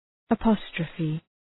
Shkrimi fonetik {ə’pɒstrəfı}